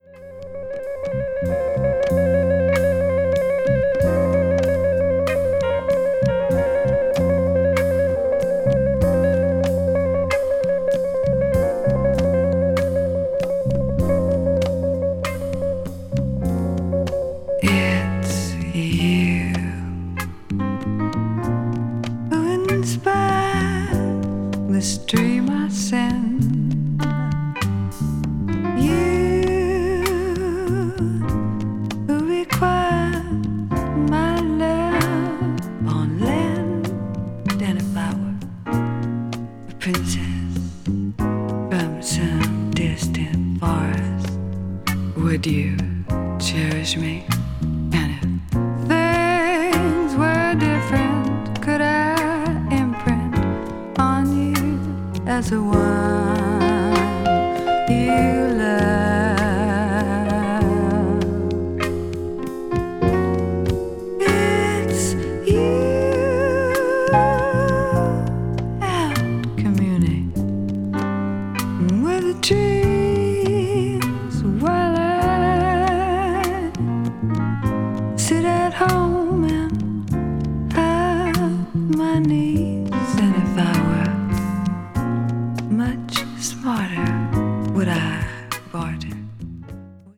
avant-garde   avant-rock   blues rock   jazz rock